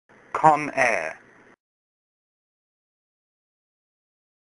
來！讓小丸子陪你邊聊明星，邊練發音，當個真正的追星族！